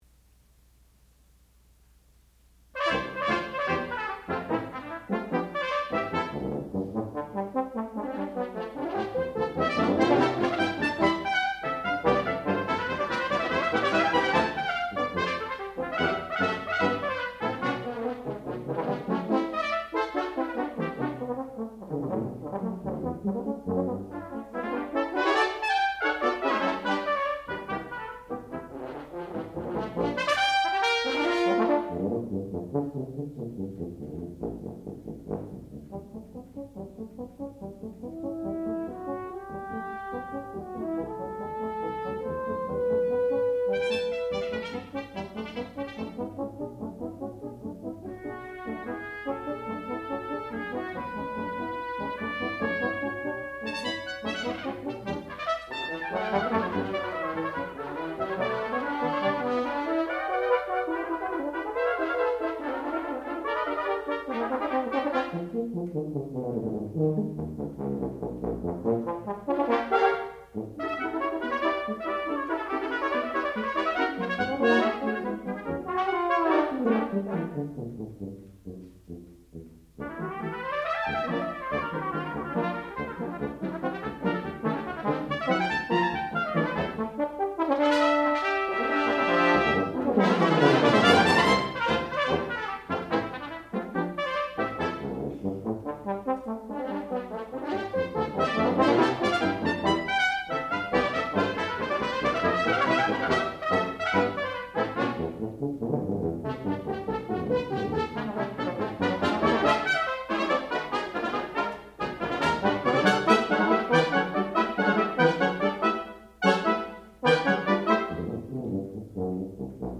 sound recording-musical
classical music
trumpet
horn